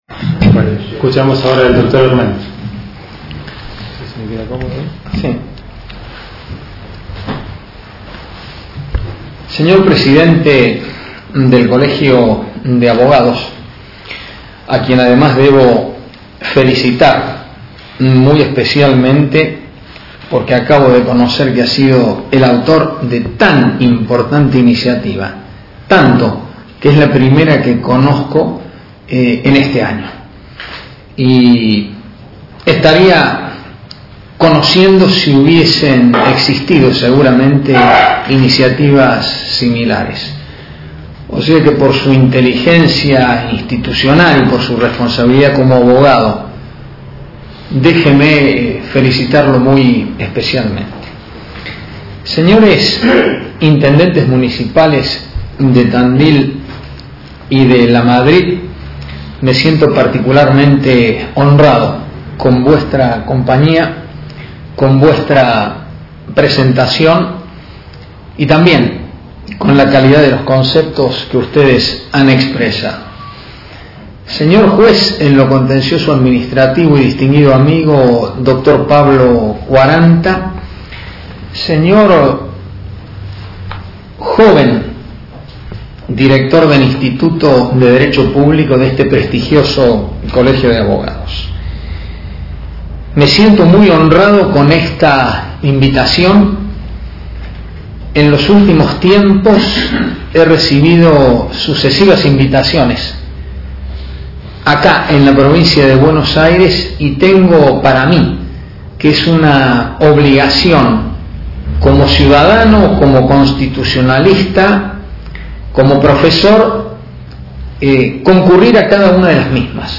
En el Centenario de la Ley Sáenz Peña el Dr. Antonio María Hernández ofreció una conferencia en Azul
El audio completo de la conferencia está disponible para su escucha online o descarga directa.